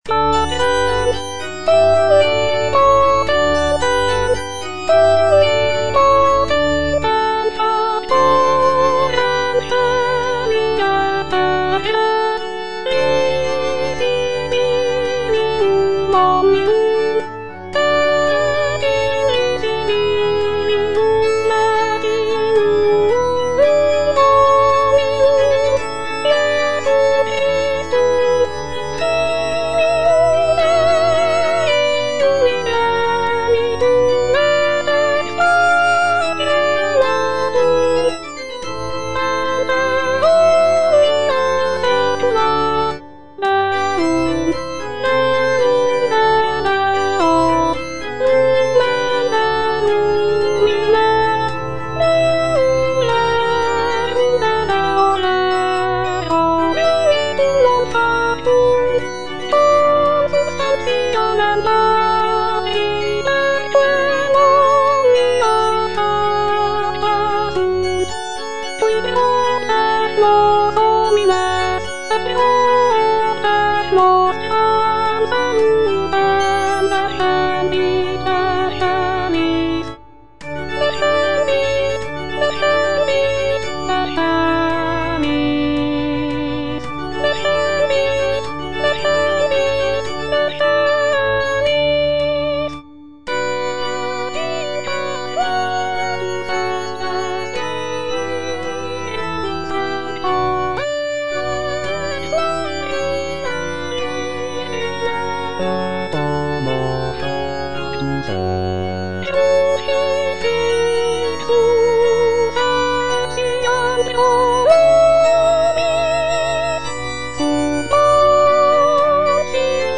W.A. MOZART - MISSA BREVIS KV194 Credo - Soprano (Voice with metronome) Ads stop: auto-stop